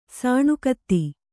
♪ sāṇu katti